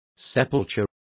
Also find out how sepulcro is pronounced correctly.